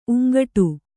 ♪ uŋgaṭu